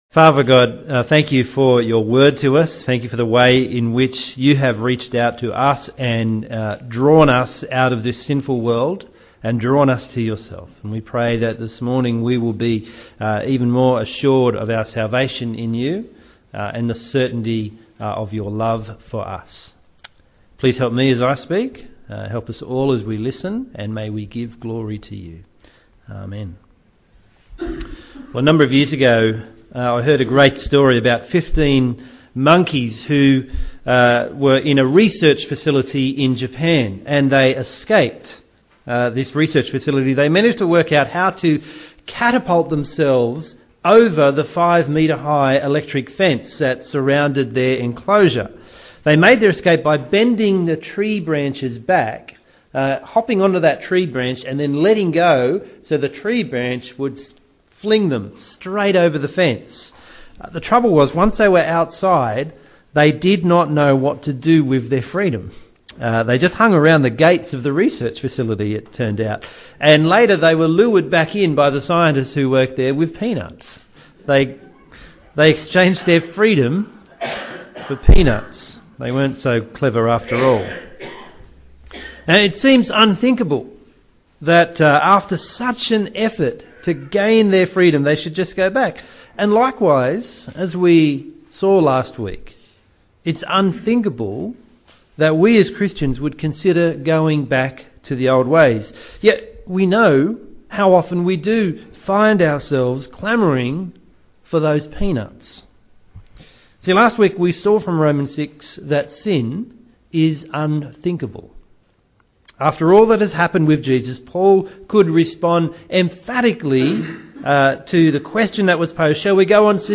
Sermon – Whose Slave are You? (Romans 6:1-7:6)